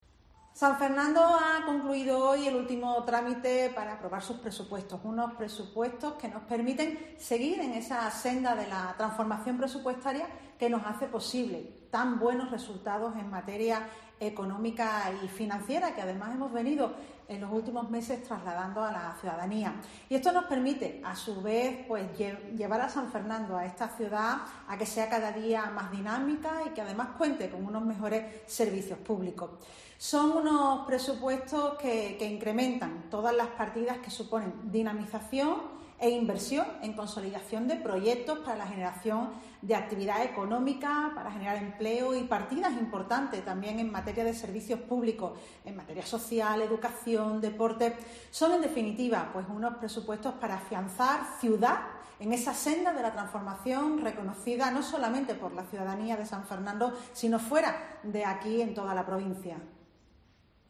AUDIO: Comparecencia de Patricia Cavada, alcaldesa de San Fernando (cÁDIZ), sobre la aprobación de los PRESUPUESTOS 2